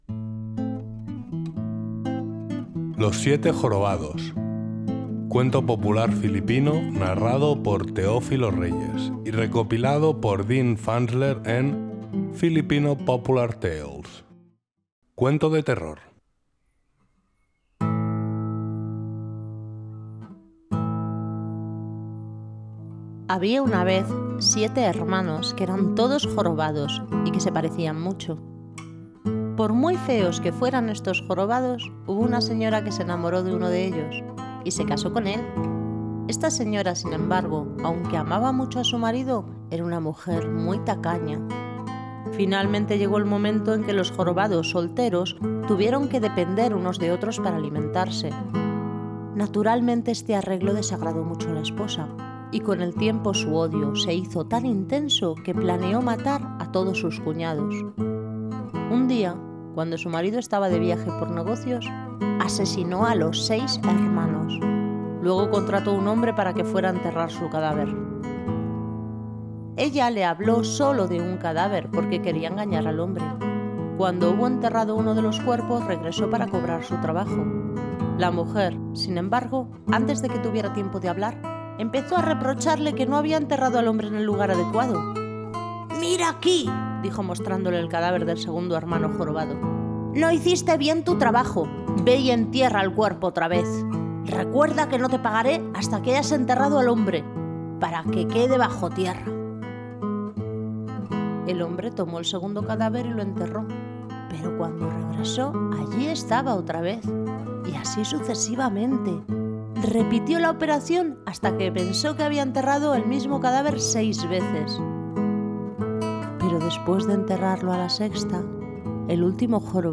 los-siete-jorobados-cuento-filipino.mp3